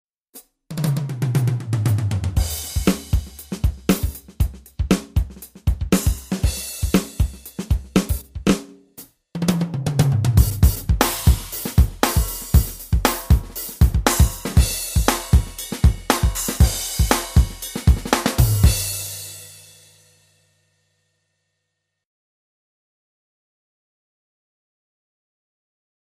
Drums
Sound - Drums.mp3